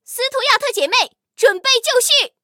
M5斯图亚特编入语音.OGG